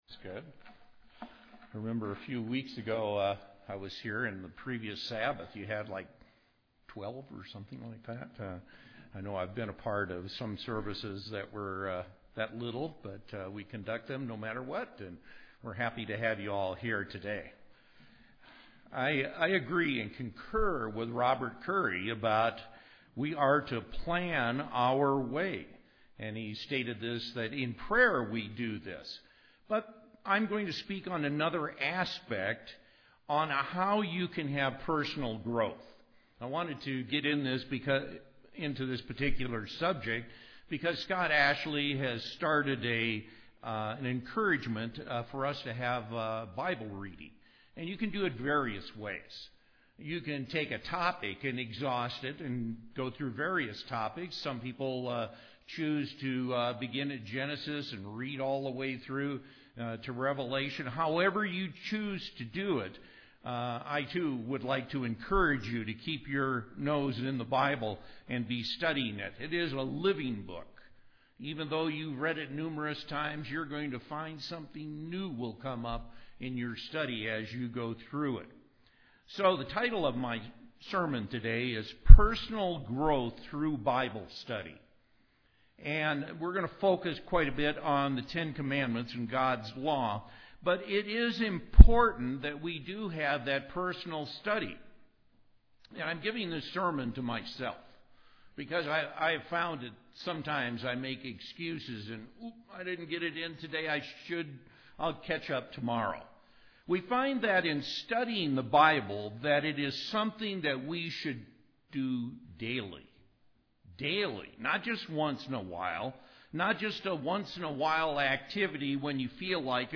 Sermons
Given in Colorado Springs, CO